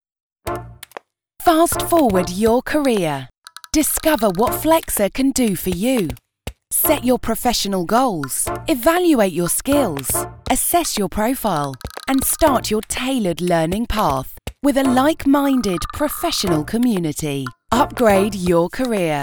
Full time female British Voice Artist. Warm , clear confident voice with gravatas
britisch
Sprechprobe: Sonstiges (Muttersprache):